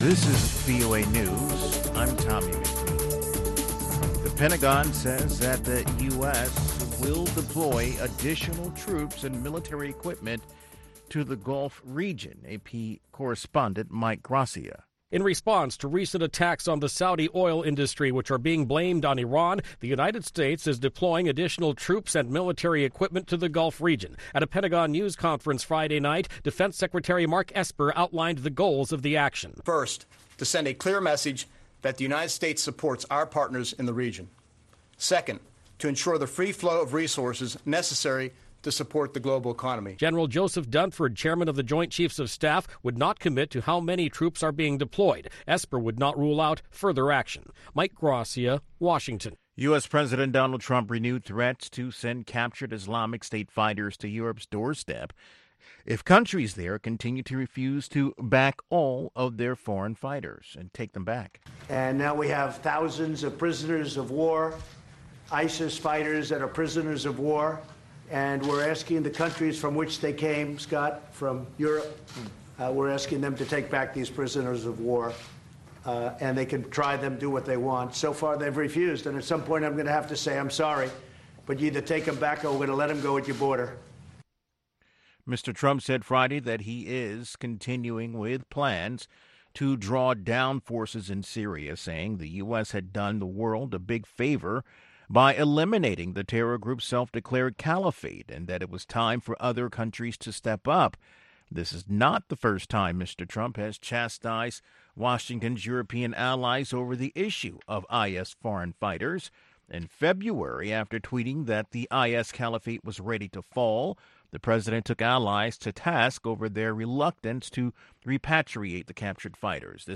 exclusive interviews